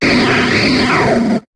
Cri de Vacilys dans Pokémon HOME.